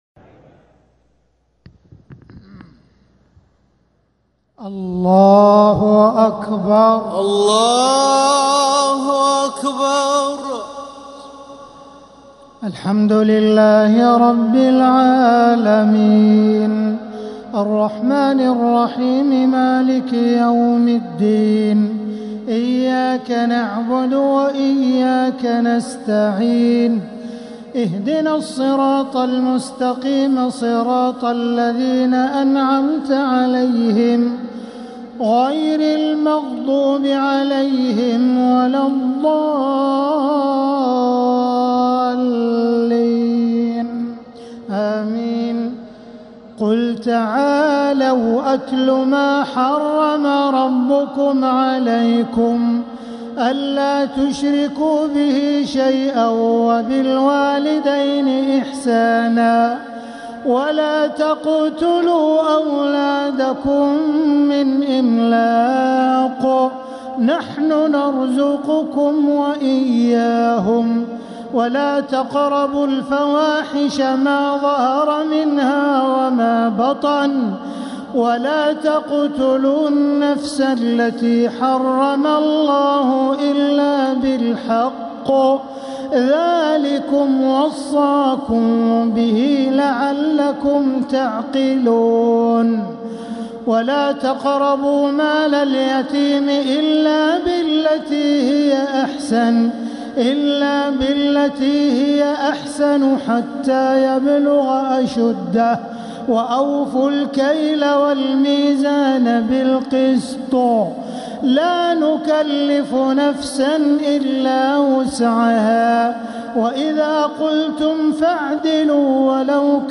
تراويح ليلة 10 رمضان 1447هـ خواتيم سورة الأنعام (151-165) | taraweeh 10th niqht ramadan1447H Surah Al-Anaam > تراويح الحرم المكي عام 1447 🕋 > التراويح - تلاوات الحرمين